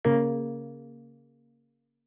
shutdown.wav